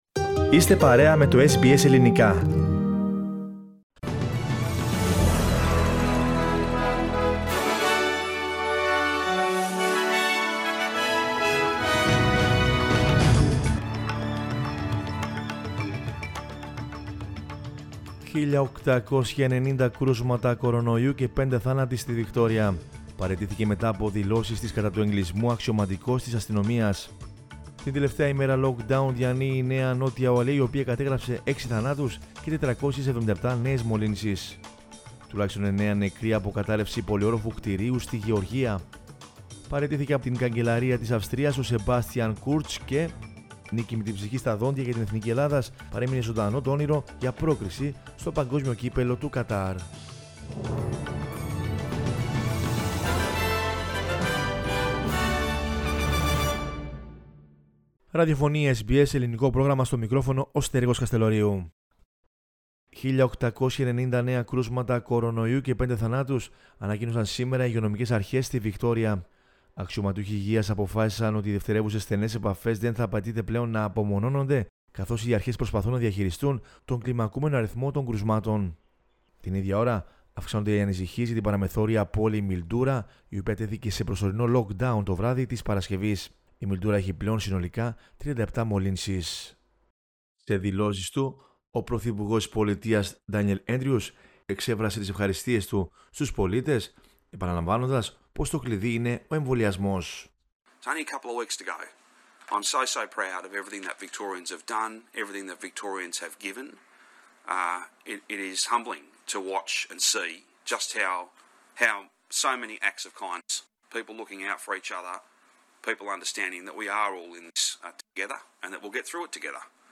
News in Greek from Australia, Greece, Cyprus and the world is the news bulletin of Sunday 10 October 2021.